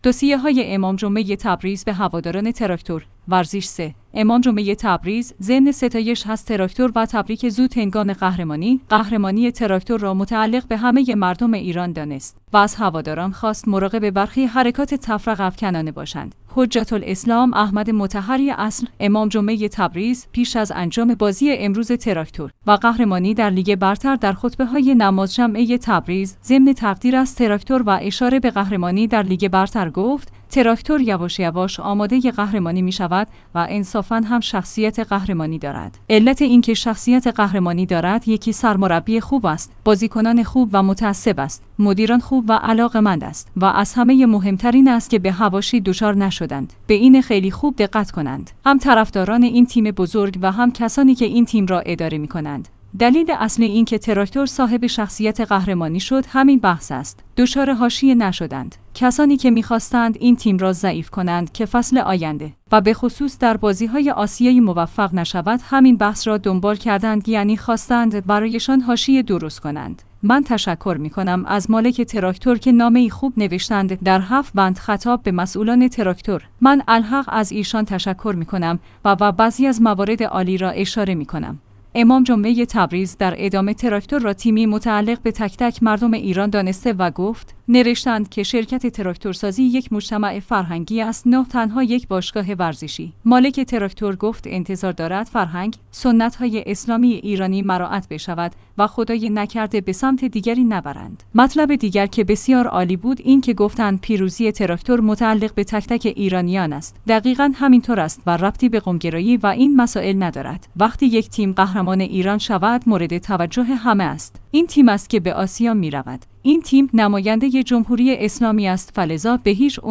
توصیه‌های امام‌جمعه تبریز به هواداران تراکتور